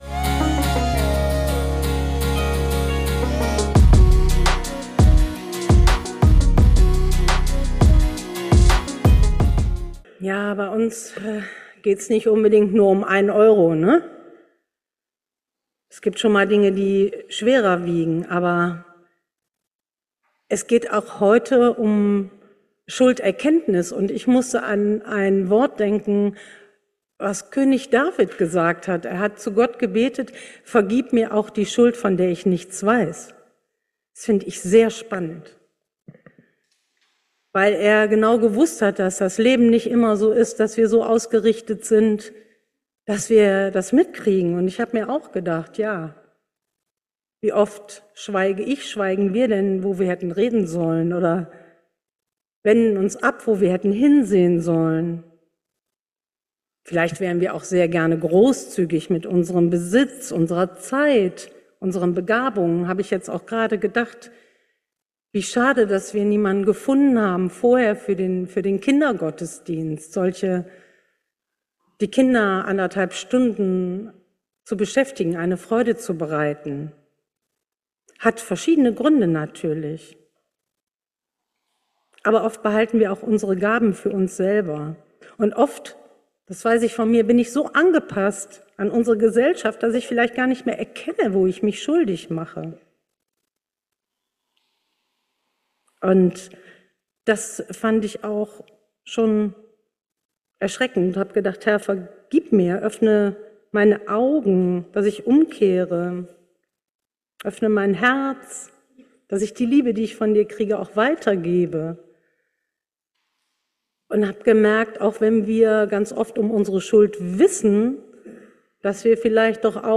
Unvergebenes versaut dir das Leben... ~ Geistliche Inputs, Andachten, Predigten Podcast